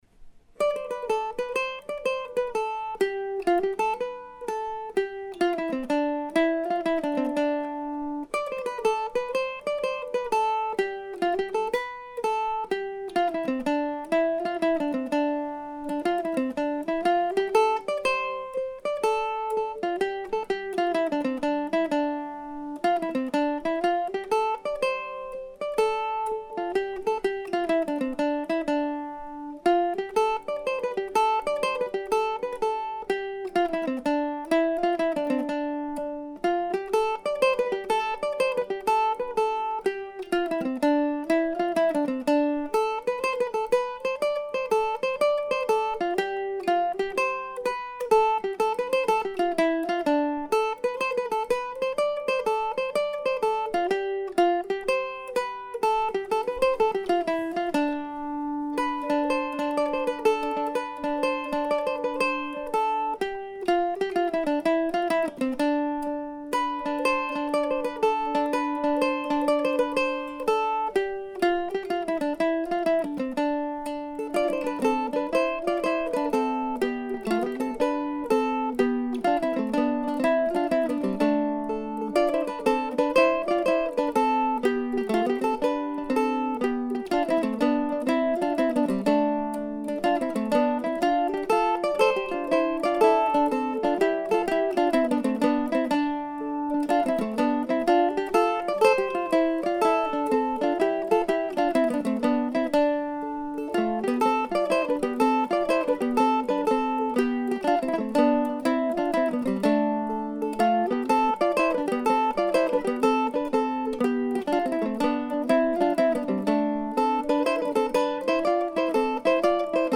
I think of Woodhaven as a medieval tune, a ductia maybe?